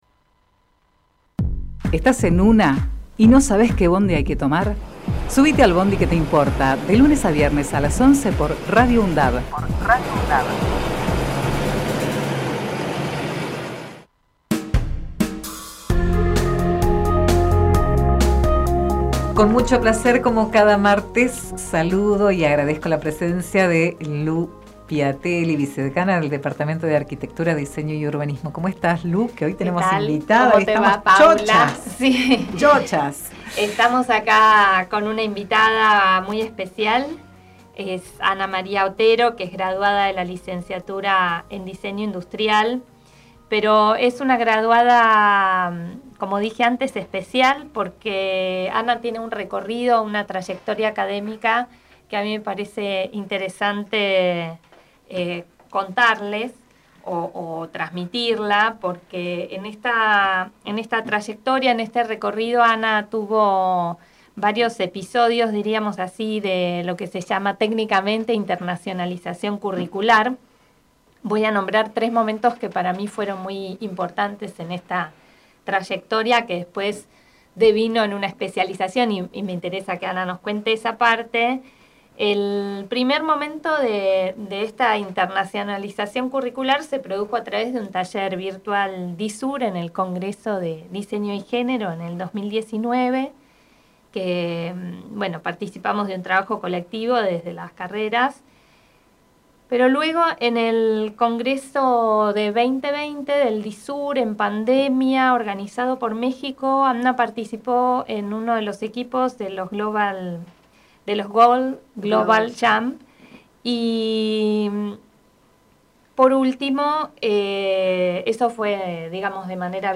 Charlamos en el estudio